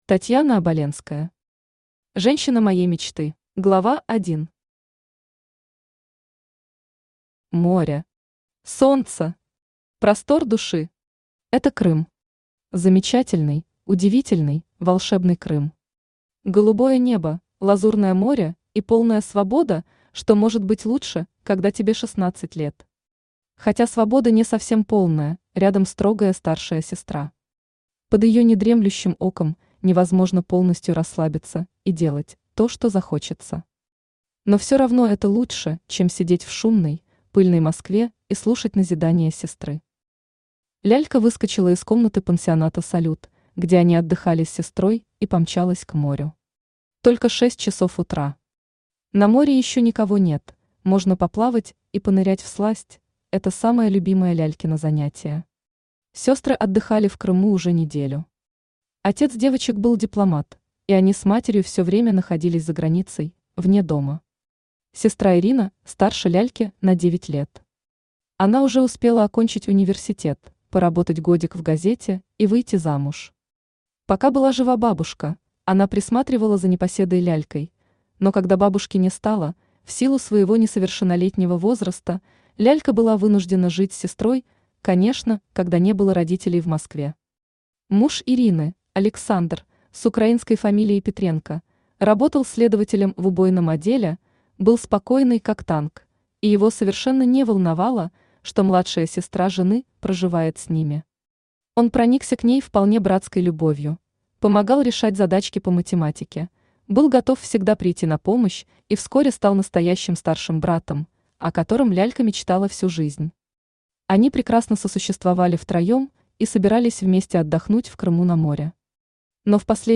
Aудиокнига Женщина моей мечты Автор Татьяна Оболенская Читает аудиокнигу Авточтец ЛитРес.